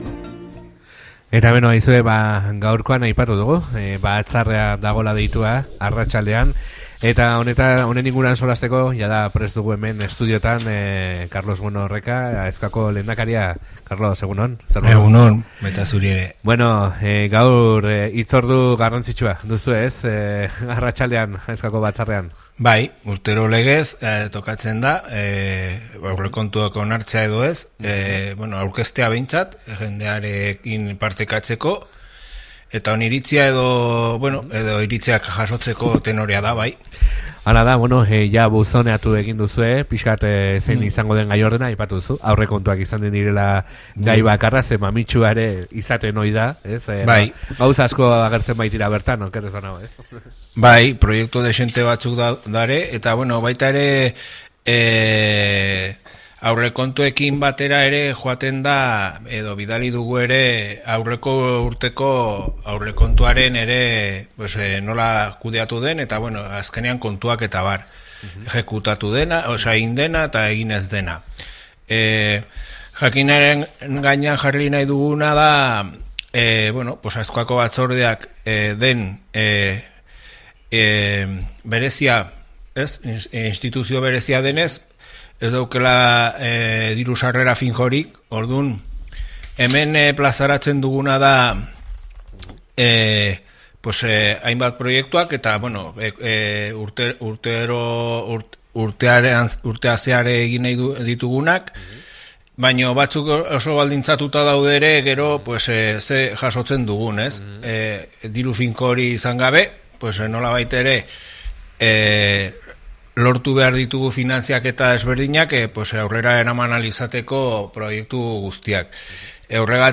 Guzti honetaz solasean eduki dugu Aezkoako Batzarreko Lehendakaria den Karlos Bueno Reka.